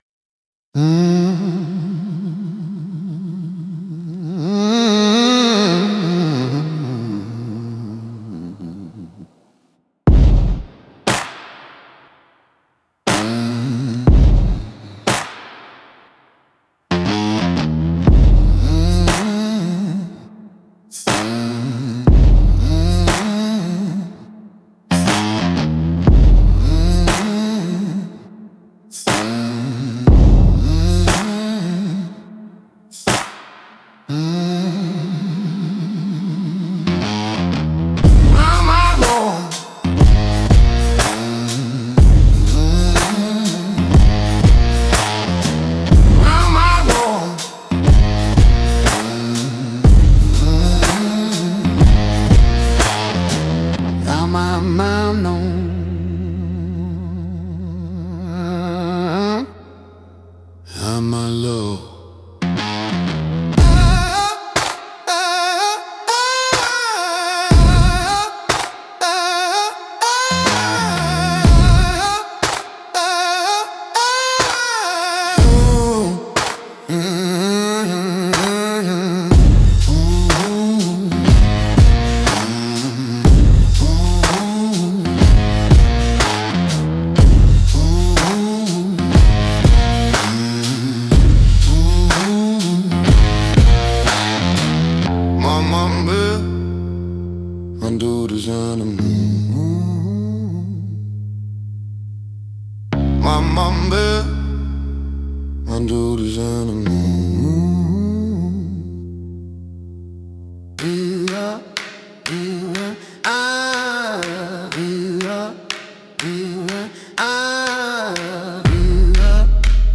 Instrumental - Deadman’s Data 2.47